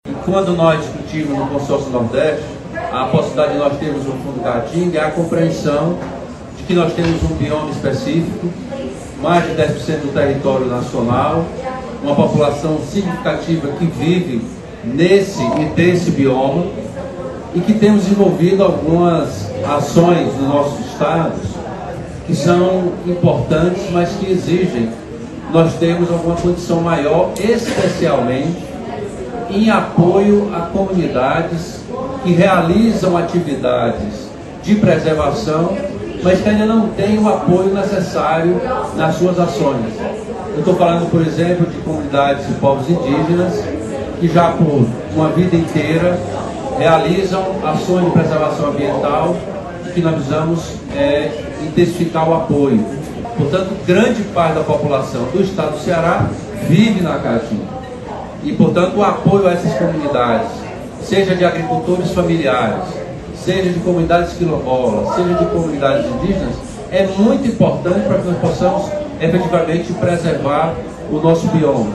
O governador Elmano de Freitas marcou presença na Conferência das Partes (COP) 28, realizada em Dubai, onde participou ativamente da apresentação de um painel.